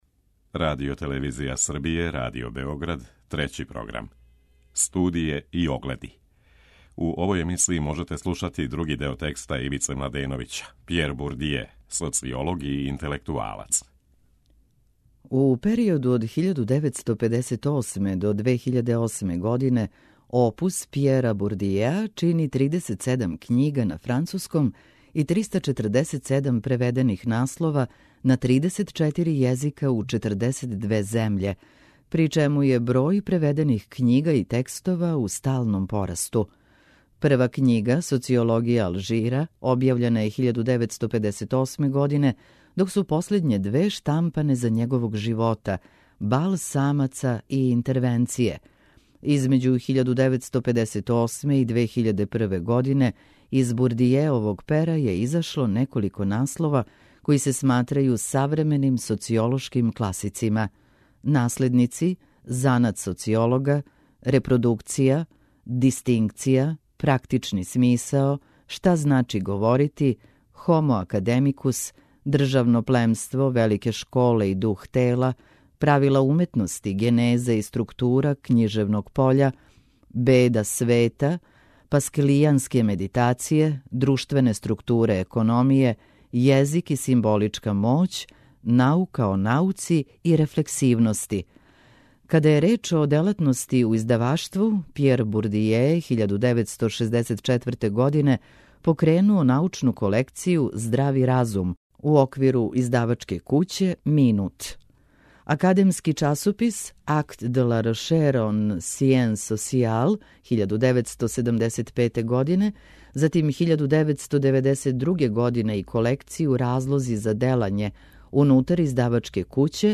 Прва говорна емисија сваке вечери од понедељка до петка.